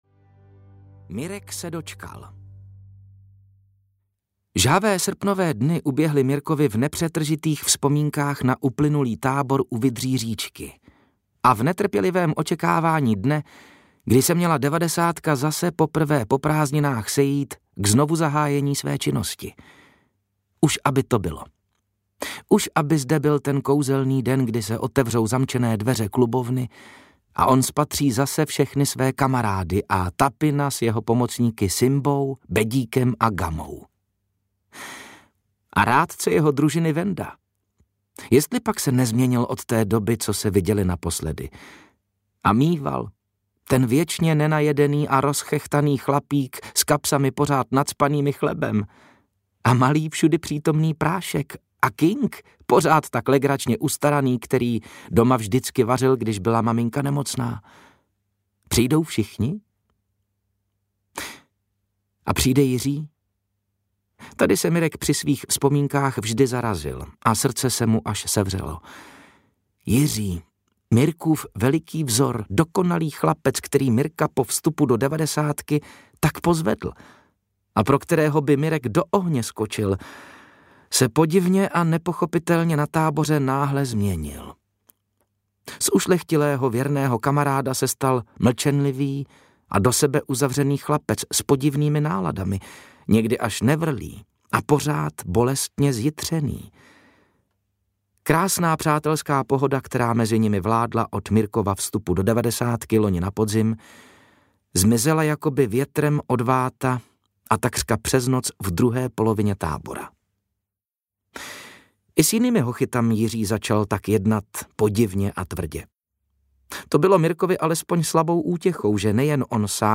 Devadesátka pokračuje audiokniha
Ukázka z knihy
• InterpretMatouš Ruml